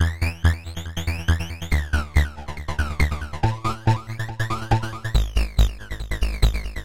Tag: 14 bpm Dubstep Loops Synth Loops 1.16 MB wav Key : Unknown